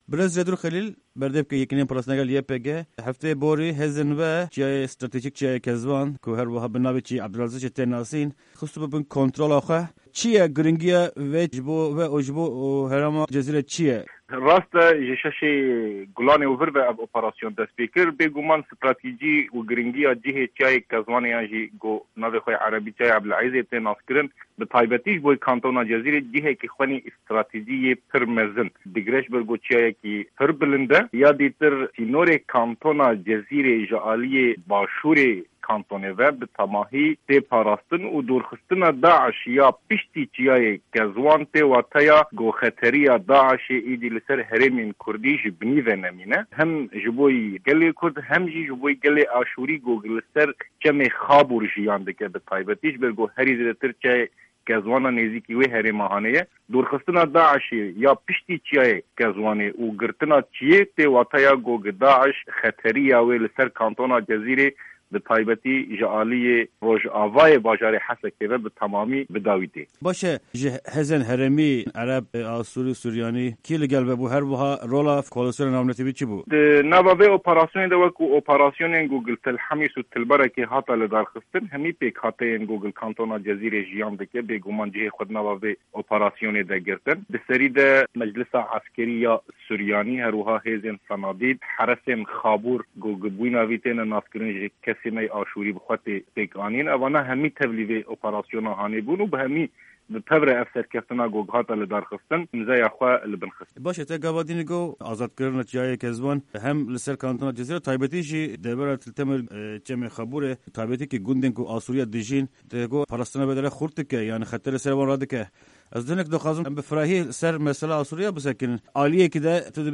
Di vê hevpeyvîna taybet de